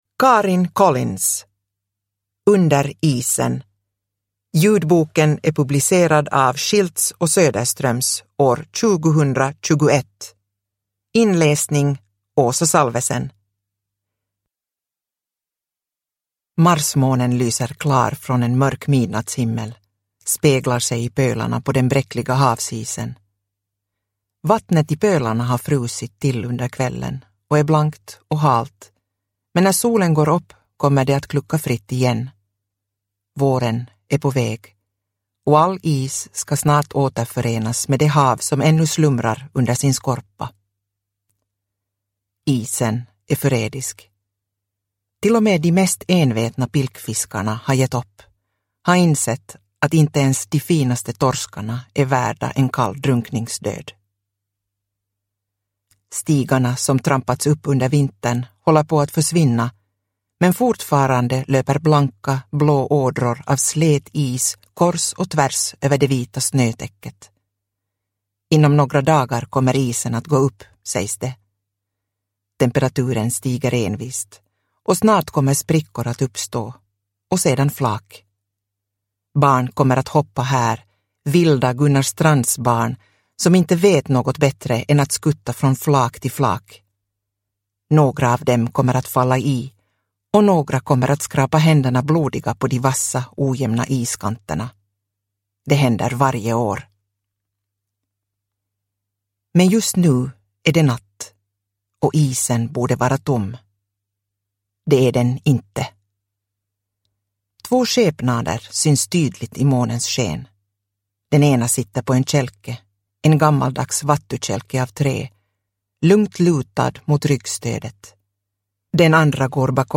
Under isen – Ljudbok – Laddas ner